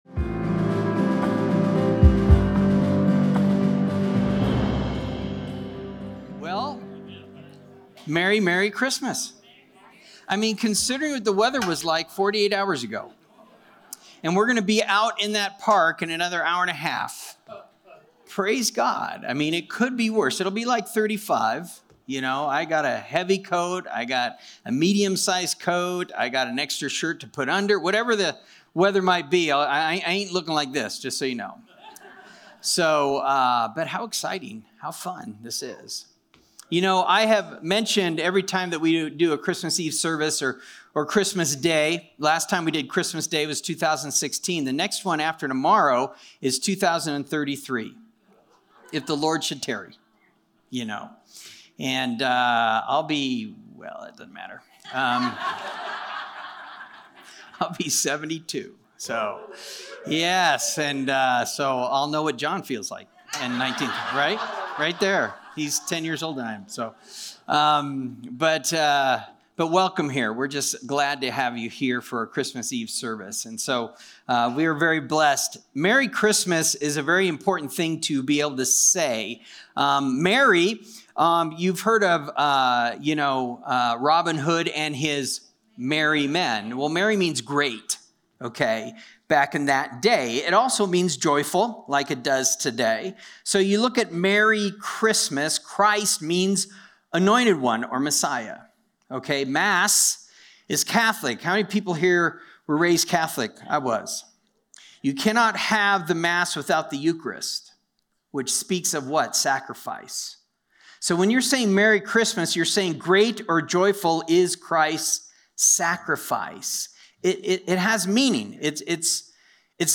Christmas Eve Service 2022